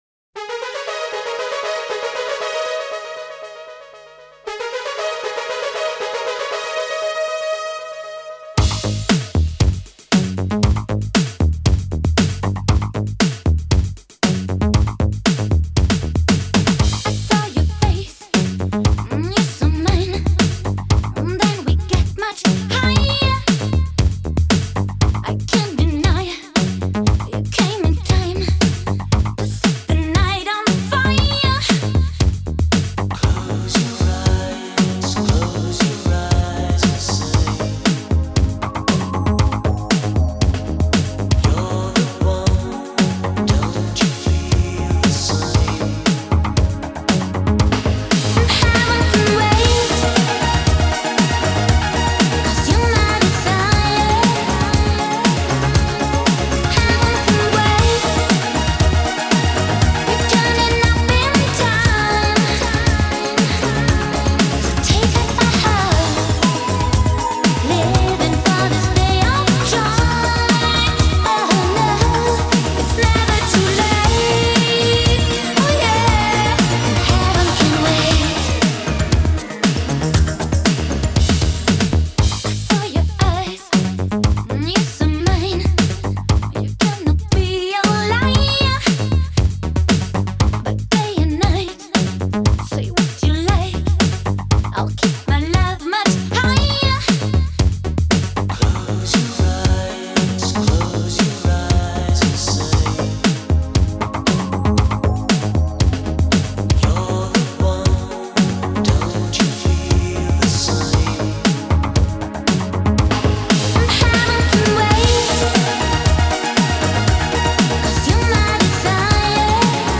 Жанры: синти-поп, танцевальная музыка,
евродиско, поп-рок, евродэнс